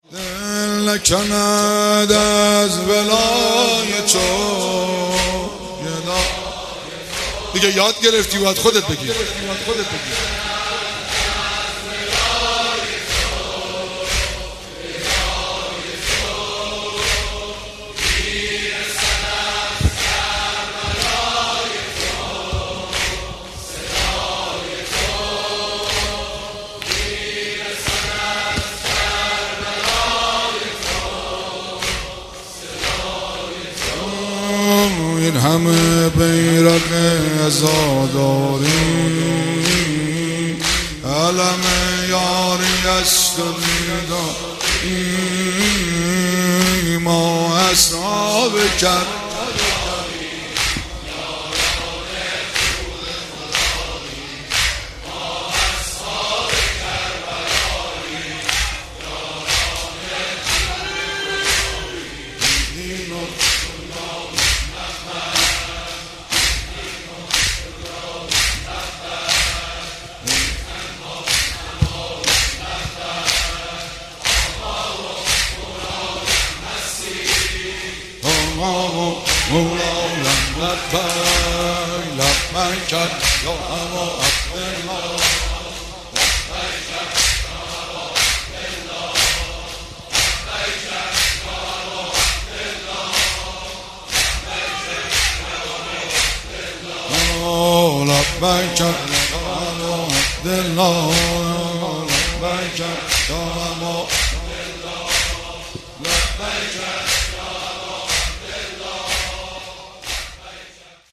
پیش زمینه شب چهارم محرم 1398